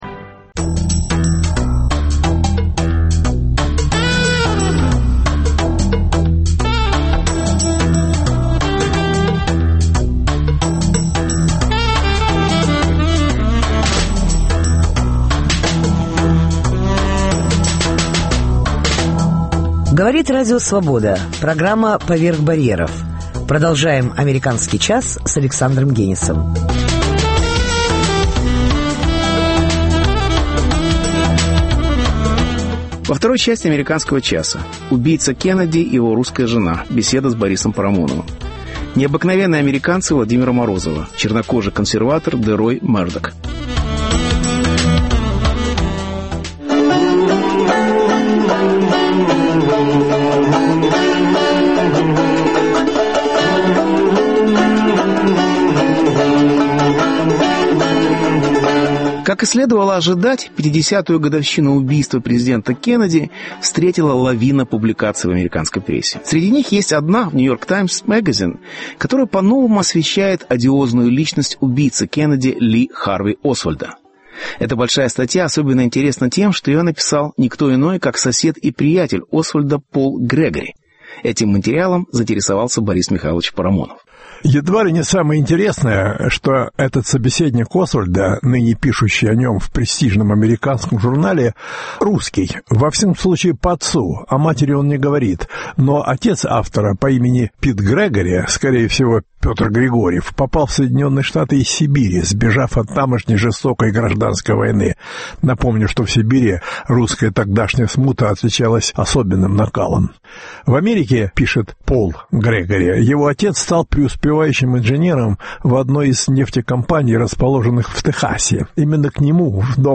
Убийца Кеннеди и его русская жена. Беседа